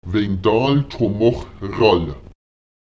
Die Imperativausdrücke -pom, -ral und -sóm können hervorgehoben werden, indem man sie anstatt ihrer grammatikalisch korrekten Position als Verbsuffixe im Prädikat nach einem Lith () am Ende des Satzes anbringt und sie kräftiger artikuliert: